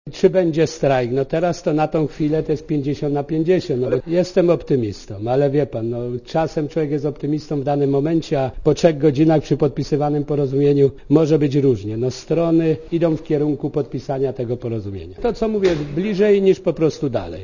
Szanse odwołania protestu oceniamy na 50% - mówi szef kolejarskiej Solidarności Stanisław Kogut.
Komentarz audio